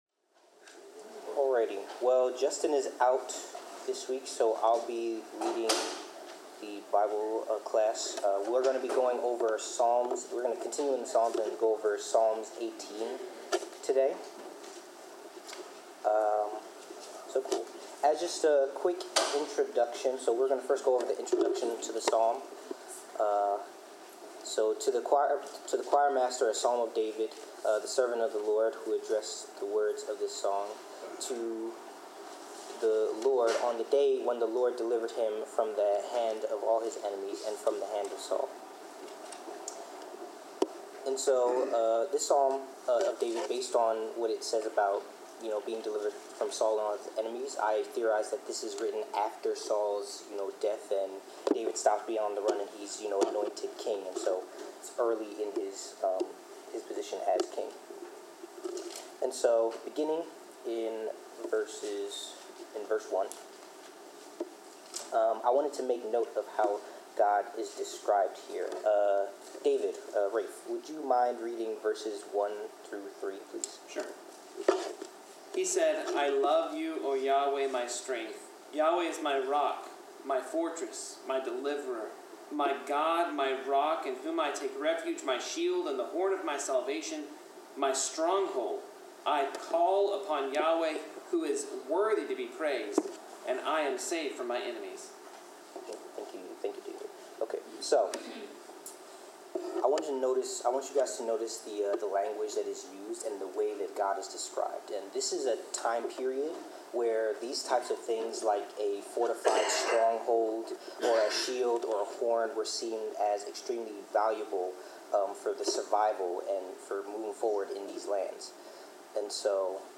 Bible class: Psalm 18
Service Type: Bible Class